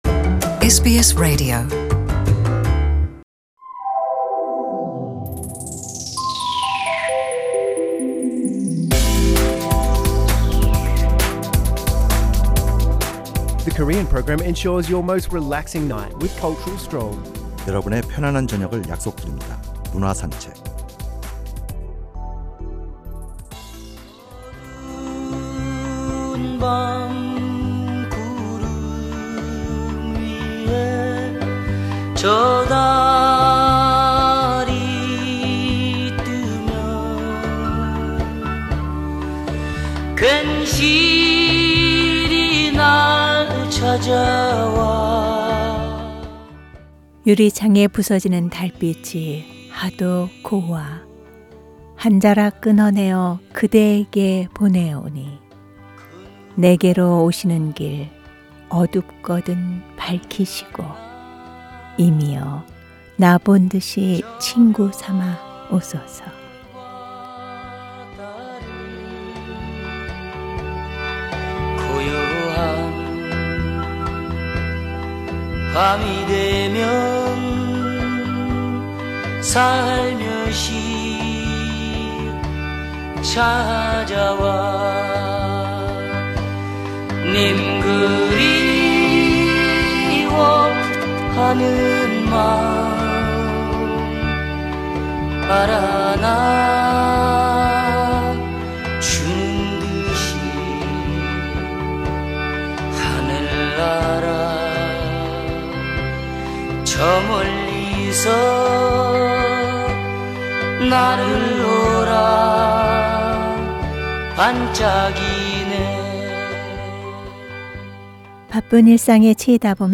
The Weekly Culture Odyssey looks into arts and artists' life with background music, and presents a variety of information on culture, which will refresh and infuse with intellectual richness.